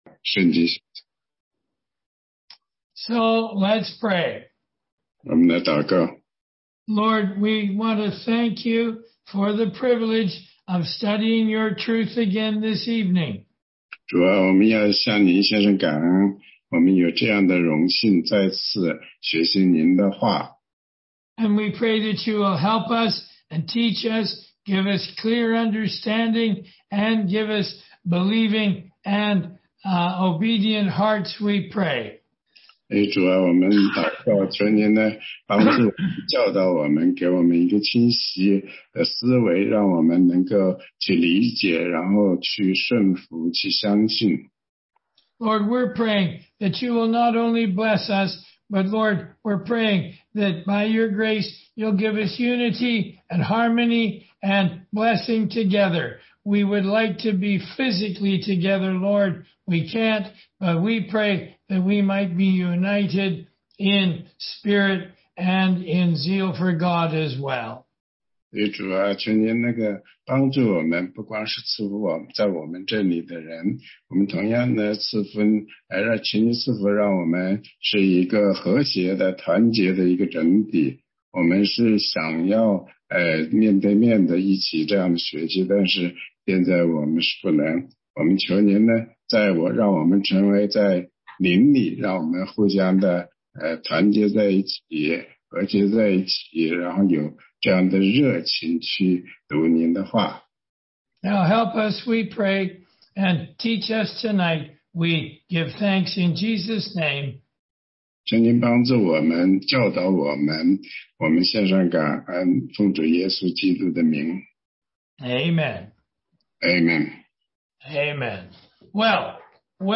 16街讲道录音 - 福音课第四十三讲